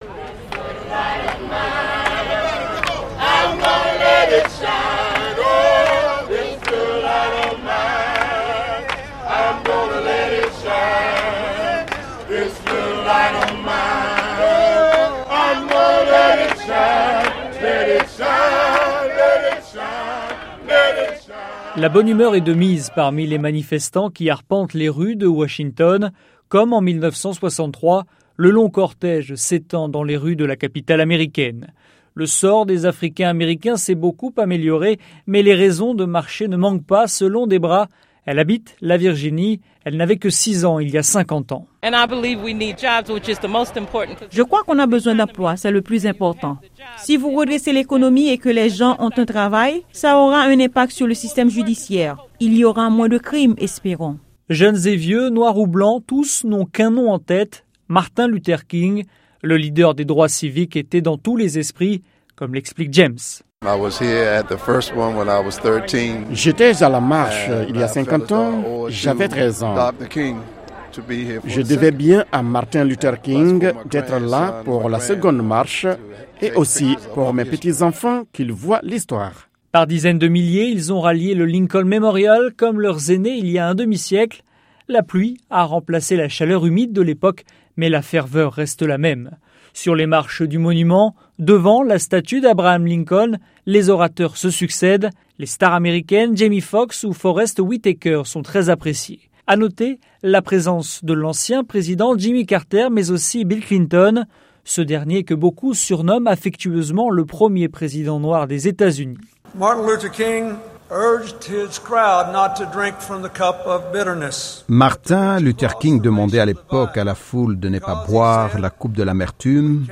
Le reportage
au Lincoln Memorial